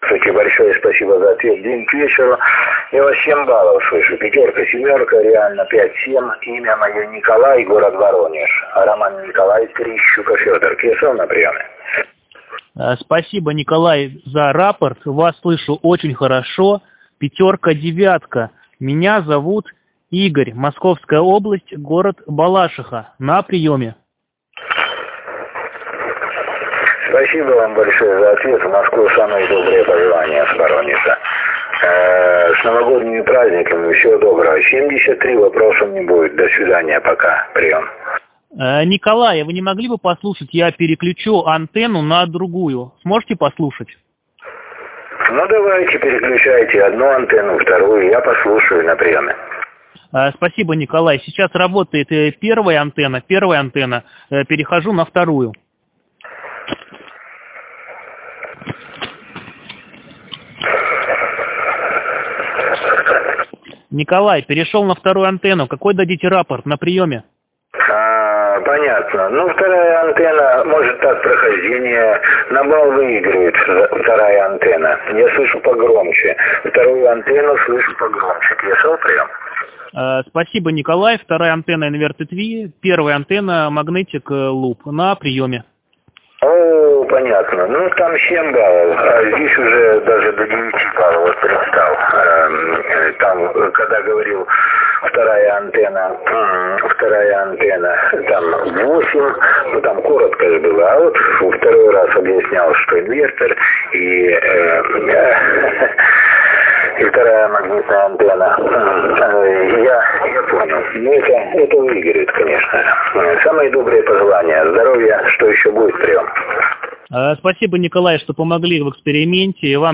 Разница в уровне сигнала составила около 1 балла.